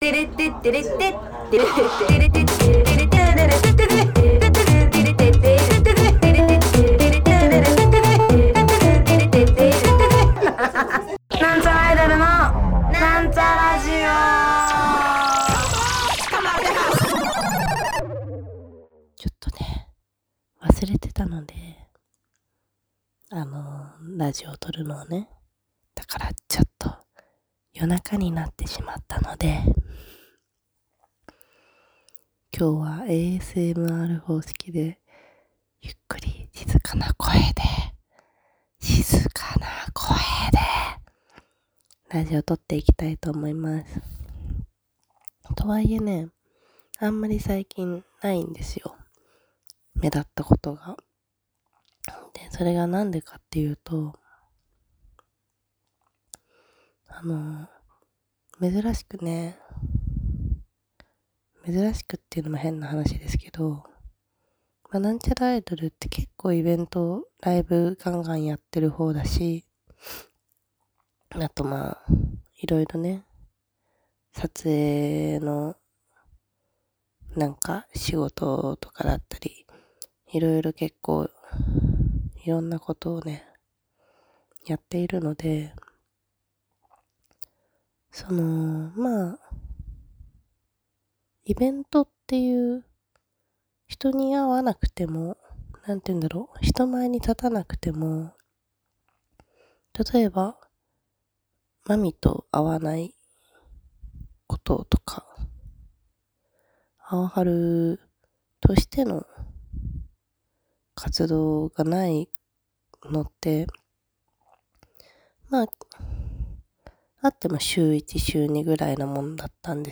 今日はASMR方式で